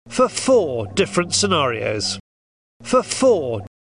A good way to illustrate this is with real utterances in which native speakers say the words for four in sequence: /fə fɔː/ or /f fɔː/. Notice the weakness of for and the prominence of four: